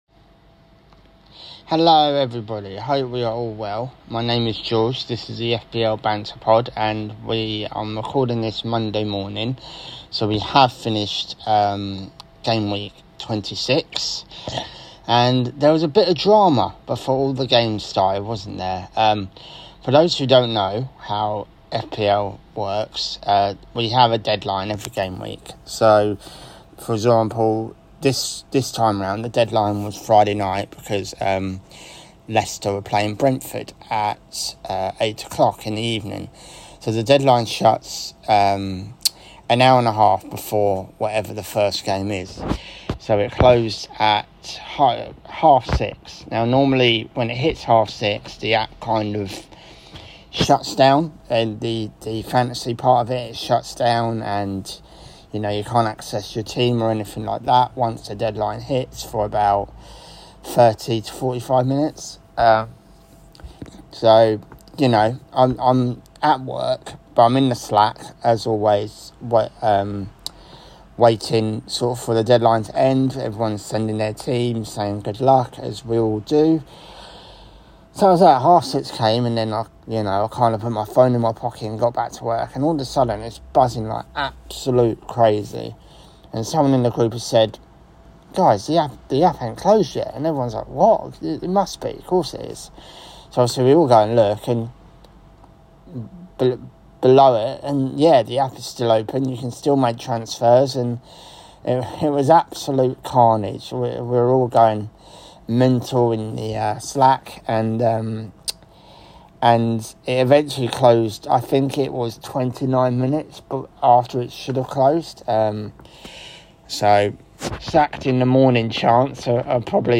FPL podcast talking GW 24 my first ever pod so I apologise for the sore throat and nervousness !!